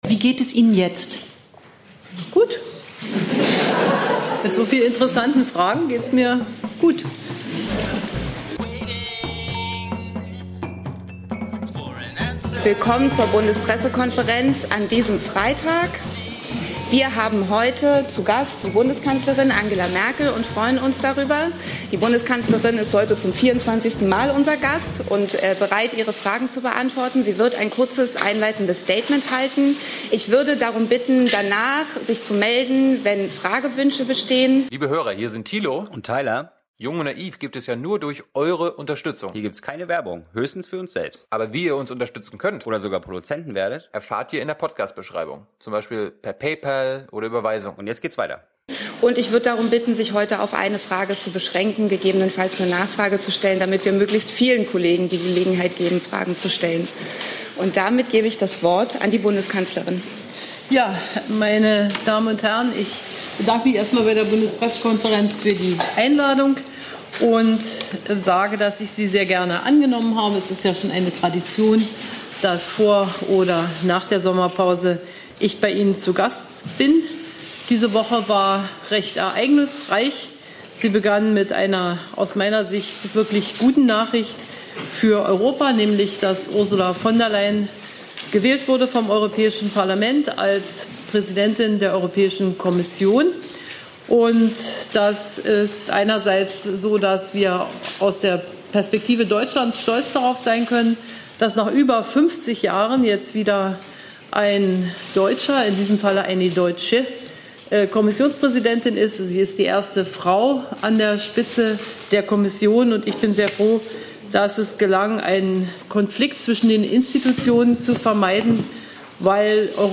BPK - Bundeskanzlerin Angela Merkel (CDU) - 19. Juli 2019 ~ Neues aus der Bundespressekonferenz Podcast